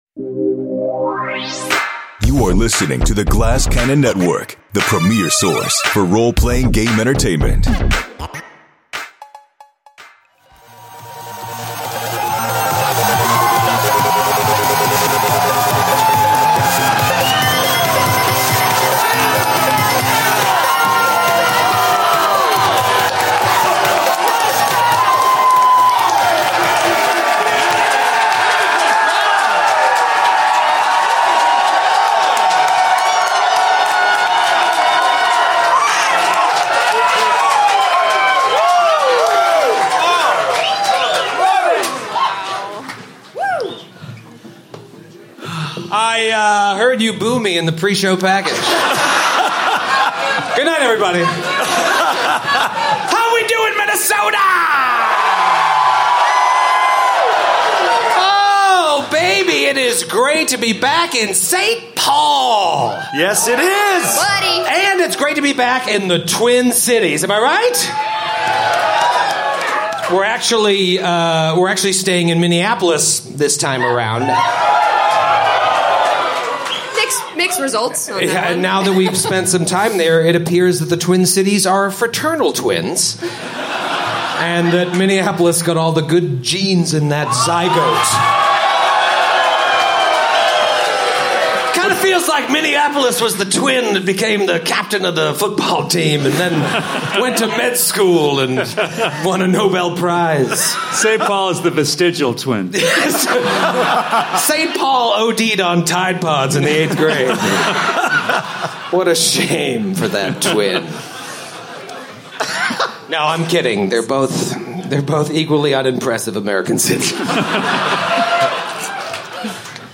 Glass Cannon Live! returns to St. Paul for a night full of huge discoveries as the heroes attempt to clear out another floor of the lunar prison.